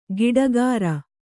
♪ giḍagāra